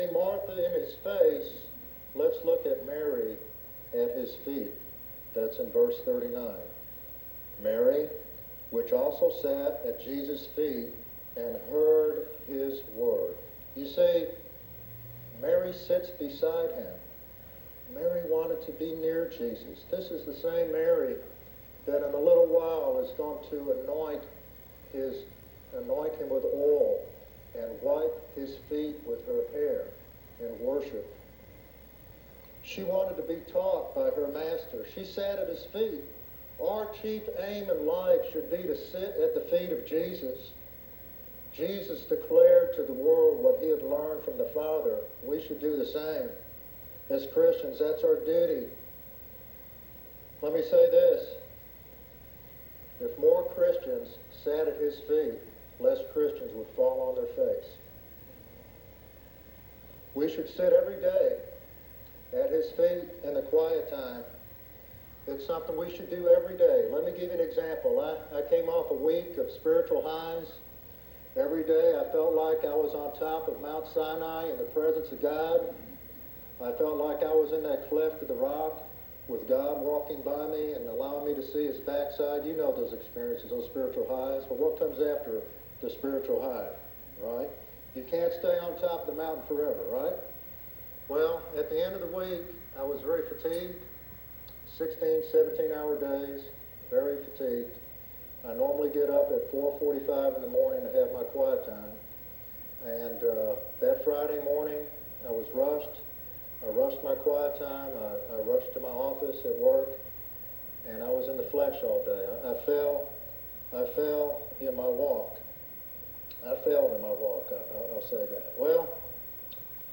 Untitled Sermon